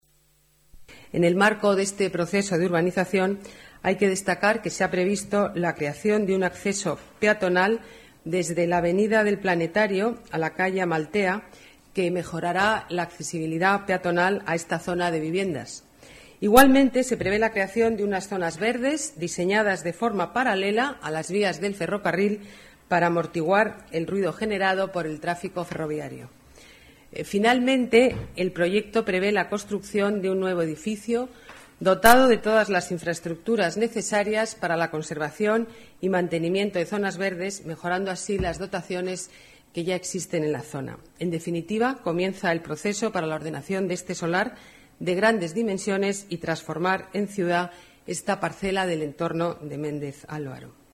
Nueva ventana:Declaraciones de la alcaldesa, Ana Botella